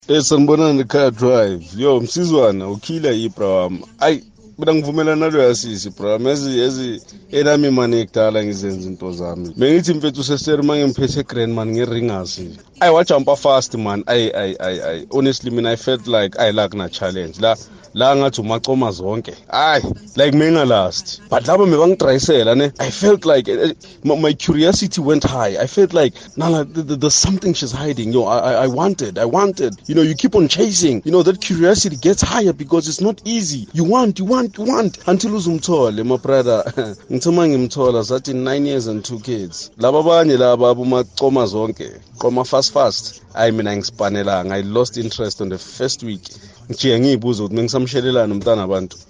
Kaya Drive listeners weigh in: